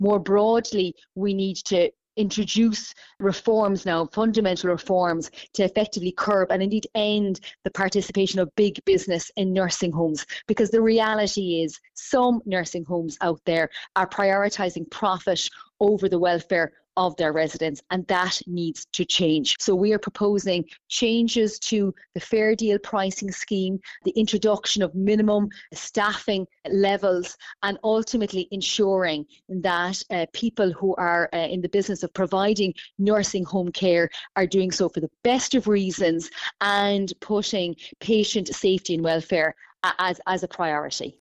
Health Spokesperson, Deputy Marie Sherlock says they are also making recommendations on the Fair Deal Scheme………….